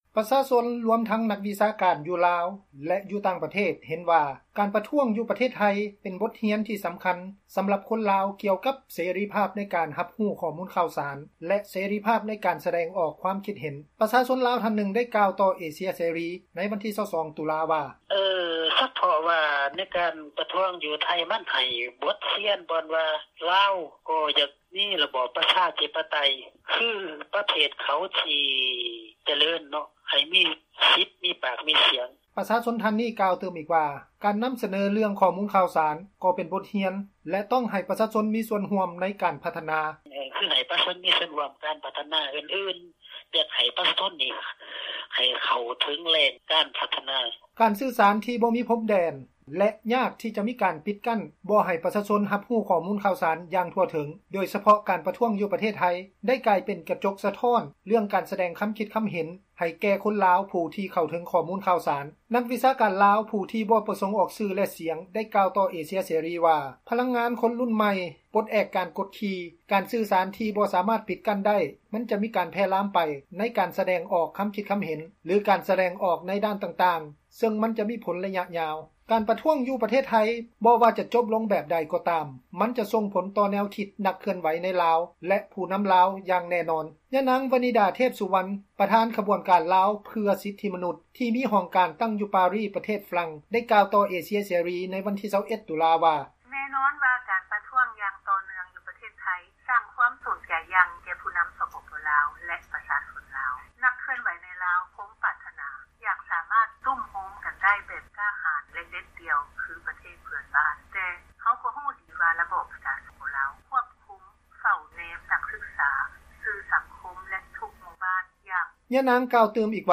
ປະຊາຊົນຢູ່ແຂວງພາກໃຕ້ຂອງລາວໄດ້ໃຫ້ສຳພາດຕໍ່ເອເຊັຽເສຣີ: